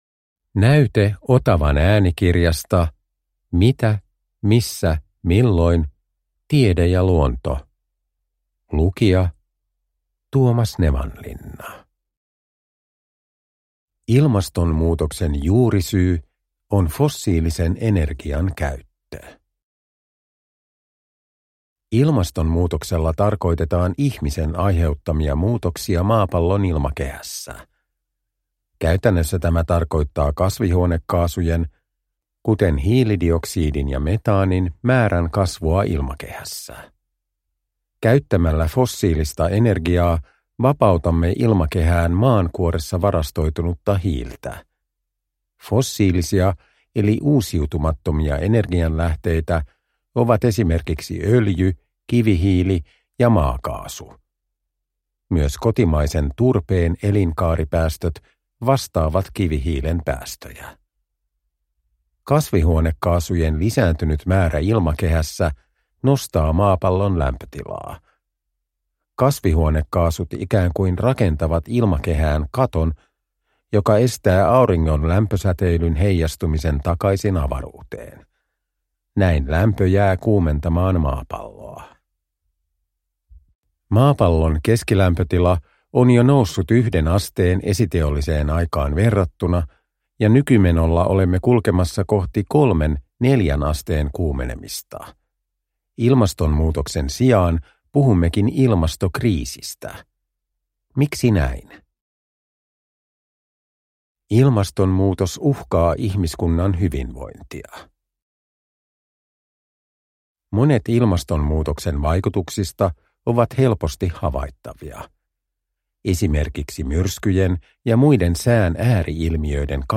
MMM Tiede ja luonto – Ljudbok – Laddas ner